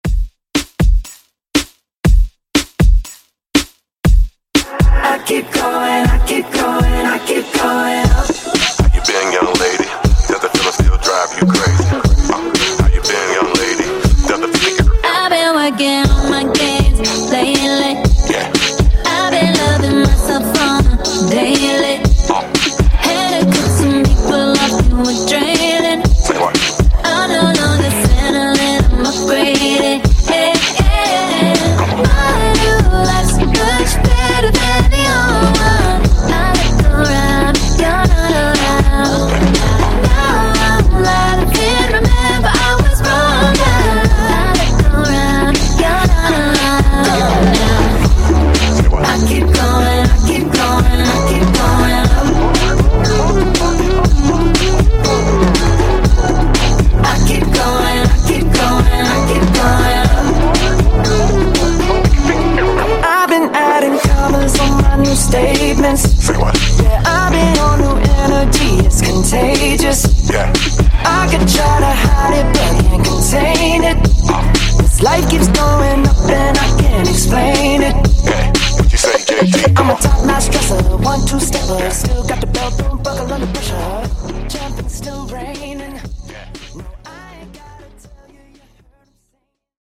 Genres: GERMAN MUSIC , HIPHOP , RE-DRUM
Dirty BPM: 95 Time